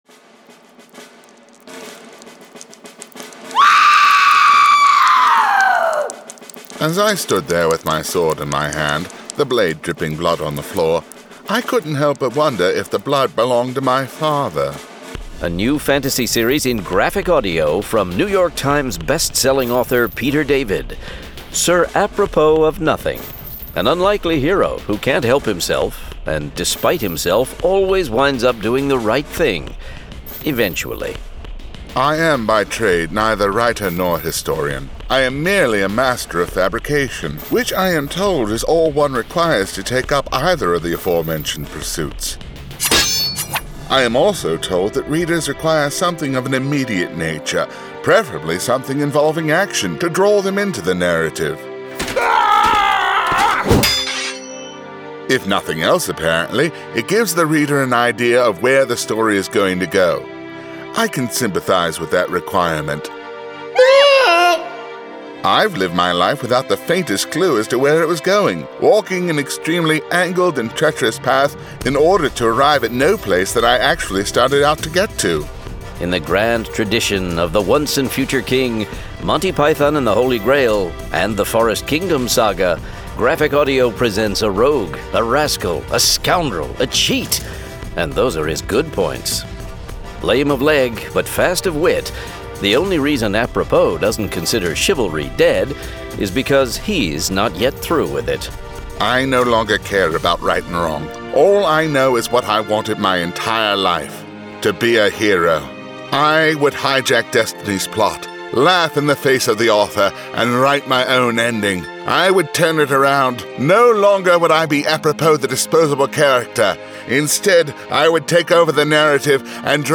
Full Cast. Cinematic Music. Sound Effects.
SIRAPROPOS0101-TRAILER.mp3